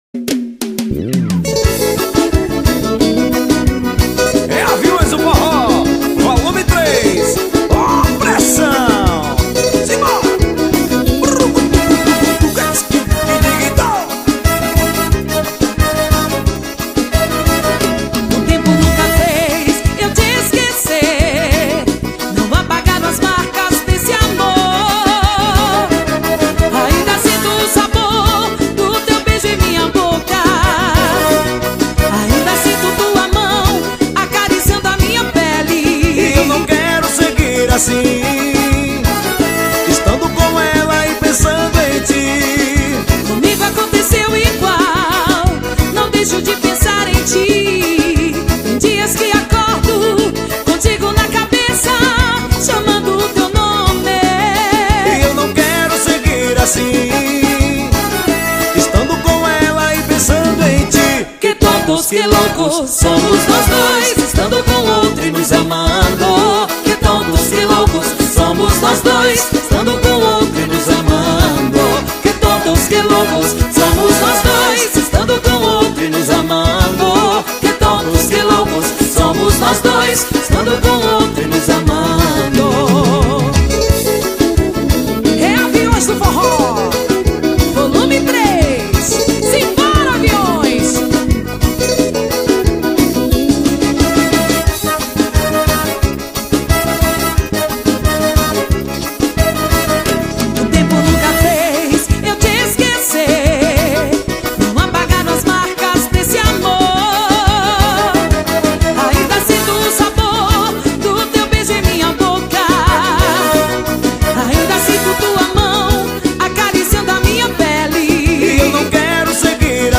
2024-12-30 11:07:45 Gênero: Forró Views